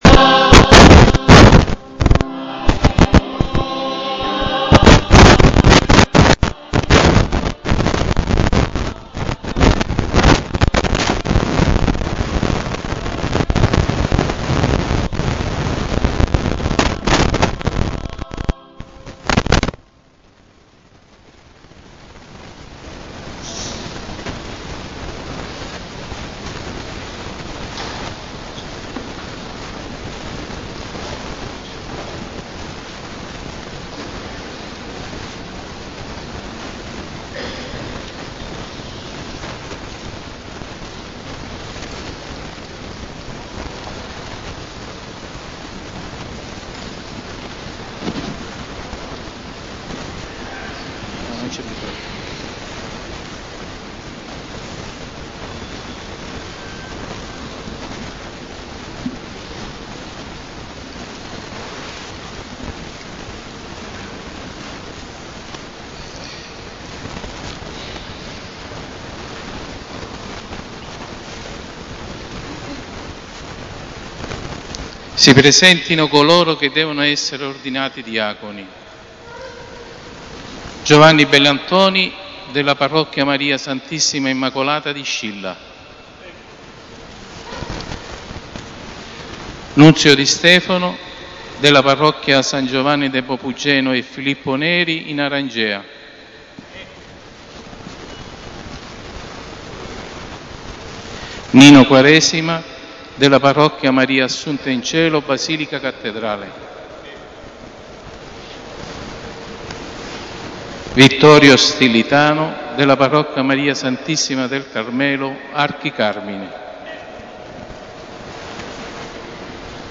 2 settembre: Ordinazione diaconale in Cattedrale: ascolta l'omelia dell'arcivescovo Morosini